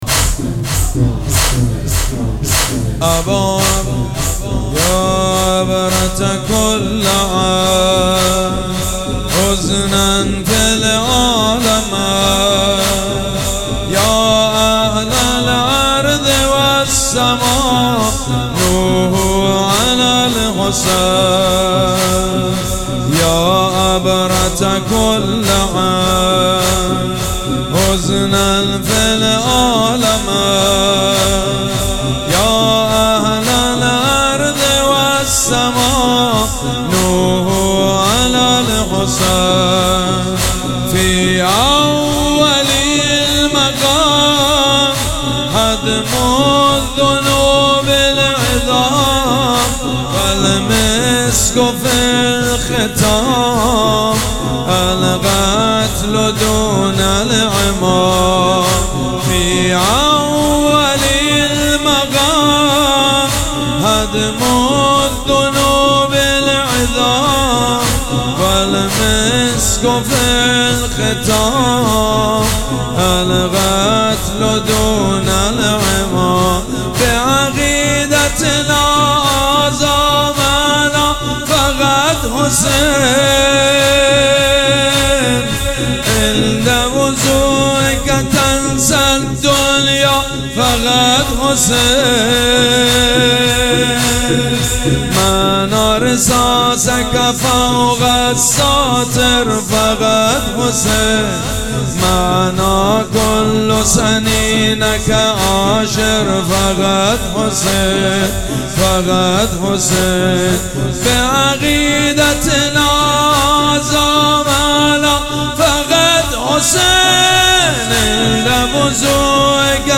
شب پنجم مراسم عزاداری اربعین حسینی ۱۴۴۷
حاج سید مجید بنی فاطمه